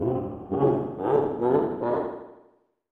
Sound effect from Super Mario 64
SM64_Bowser's_Laugh.oga.mp3